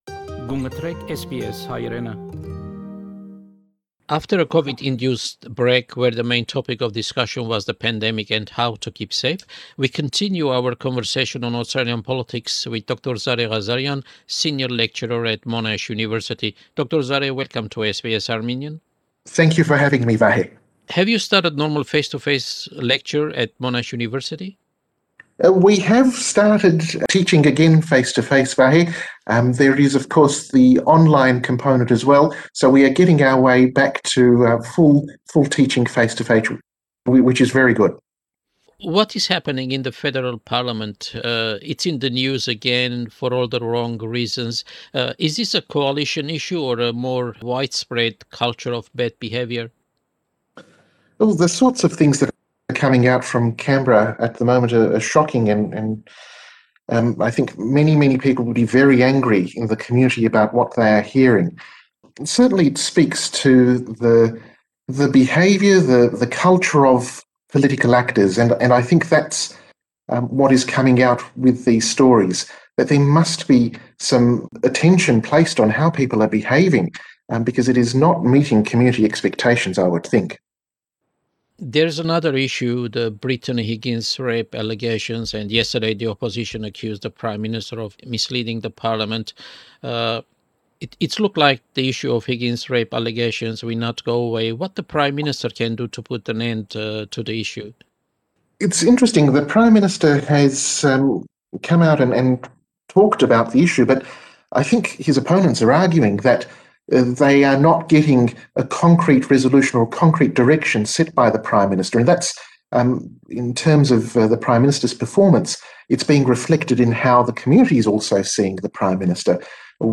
The main topic of the interview is current Australian politics.